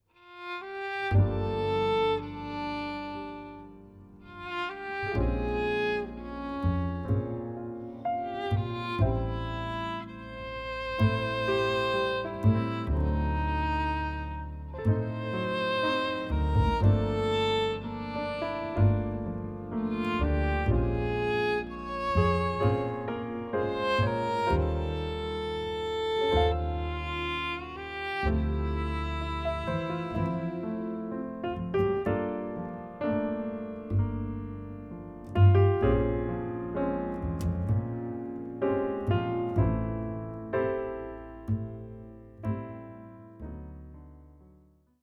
Recorded on July.26th 2022 at Studio Happiness Engineer
vln